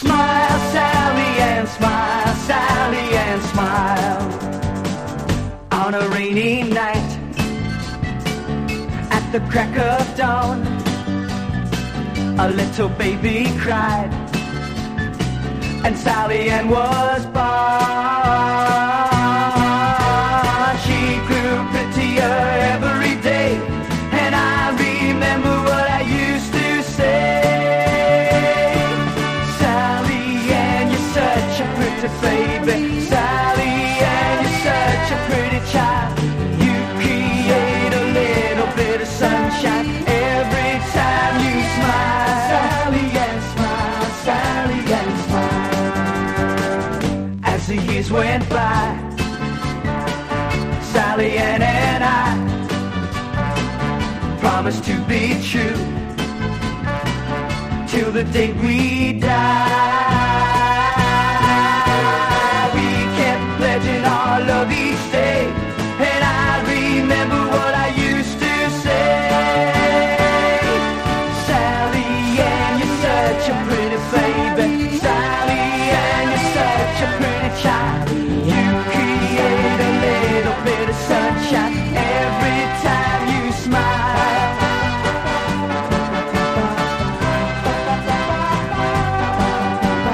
パパパ・コーラスが舞い上がるバブルガム・ソフトロック・クラシック！